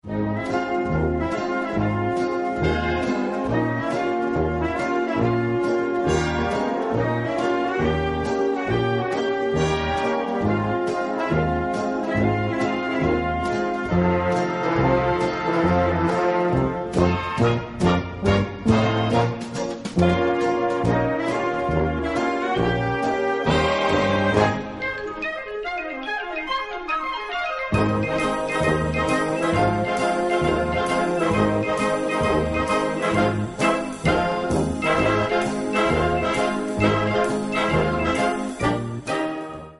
Gattung: Zither Ballade
Besetzung: Blasorchester